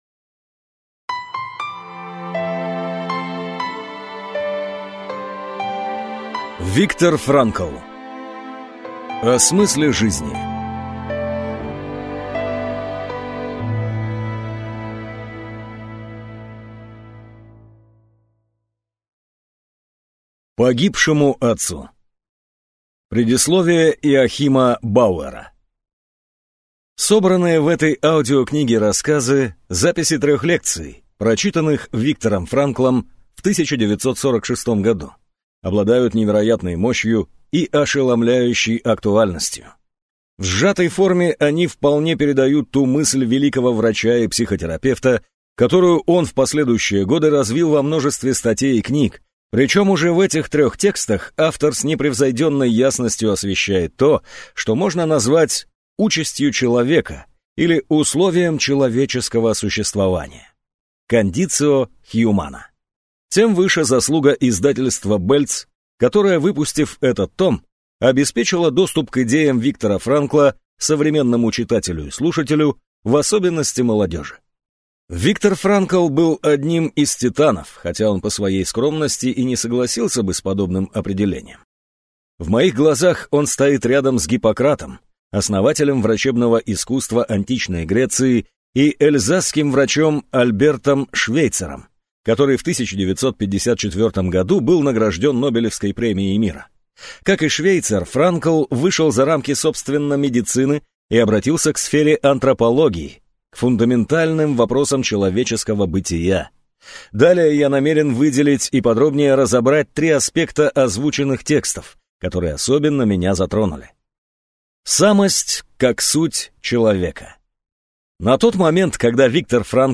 Аудиокнига О смысле жизни | Библиотека аудиокниг